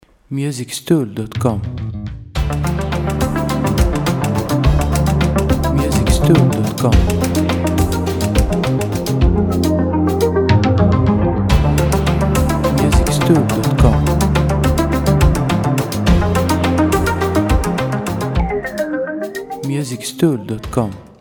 Groove & Chill Background Music for Ads, Videos & Games